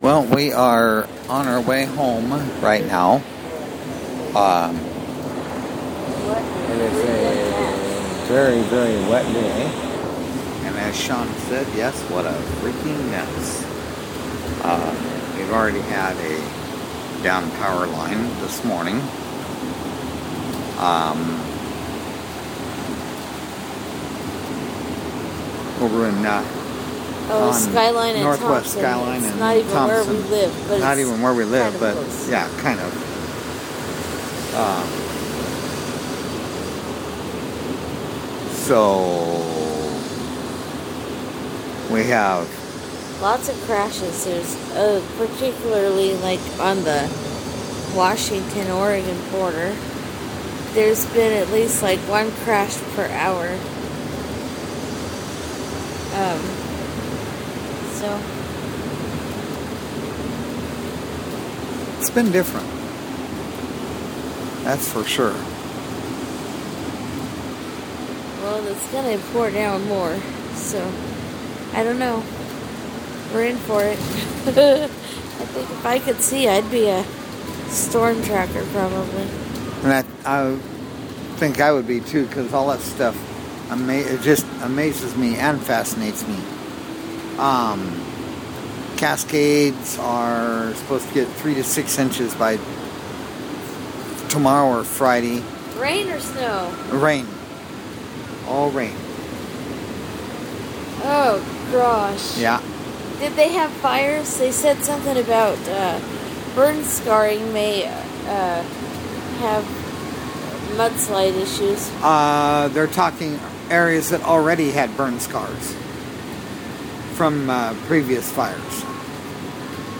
wild rainy season definitely started in Portland Oregon
we had a wild ride home the day this recording was made. and the water came down rather hard as well here in this recording.